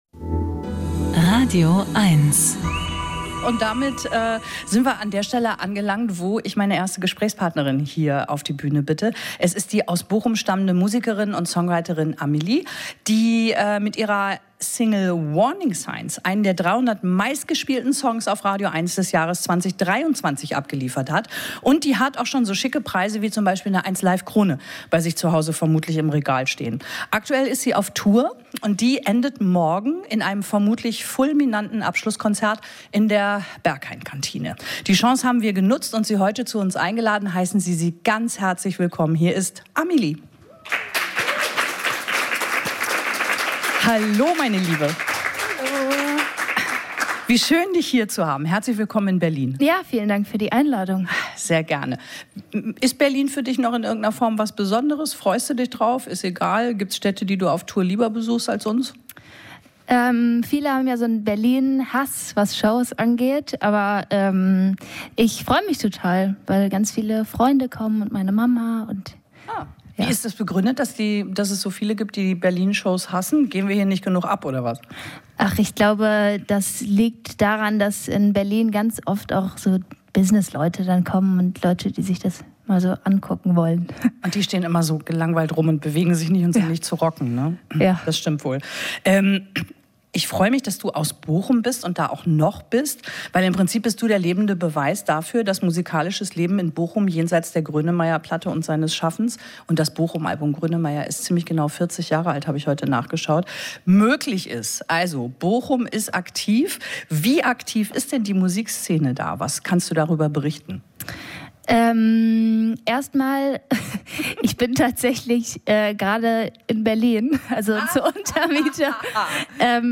sommerlich-luftigen Disco-Pop